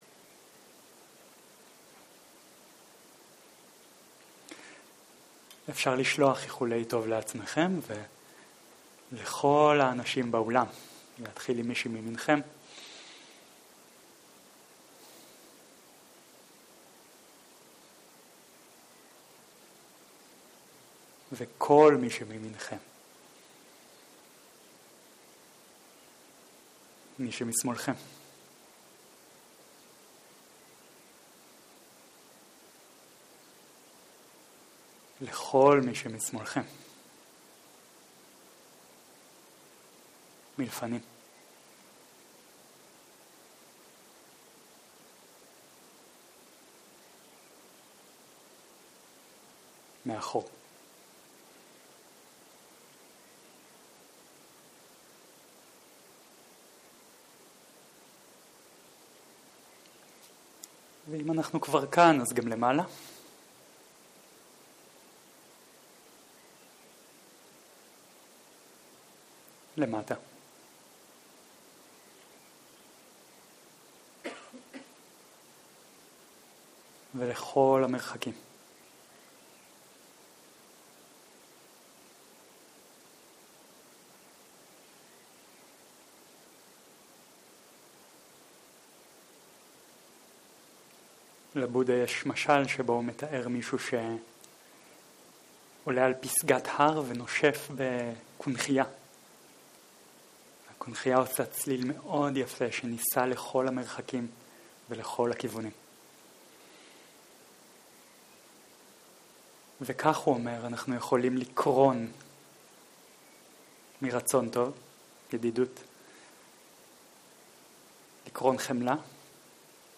ערב - שיחת דהרמה - קארמה, מחשבה, מטא
סוג ההקלטה: שיחות דהרמה